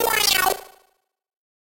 snd_electric_meow.wav